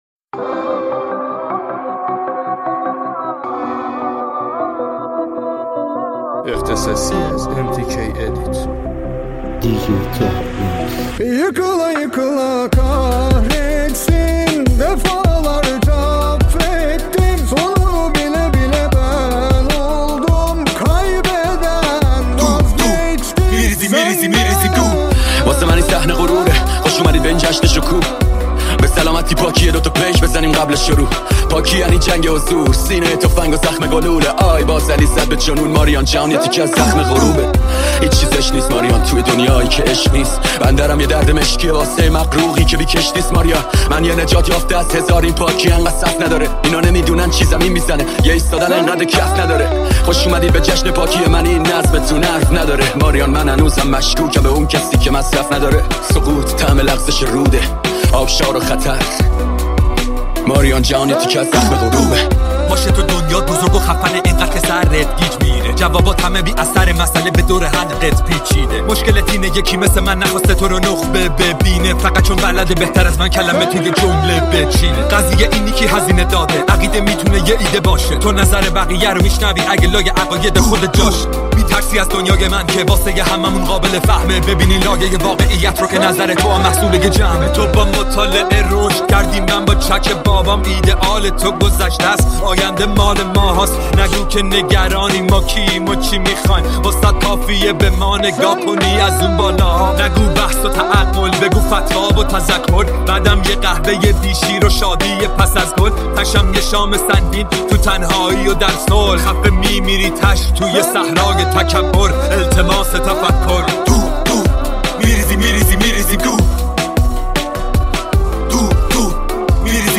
ریمیکس خفن رپی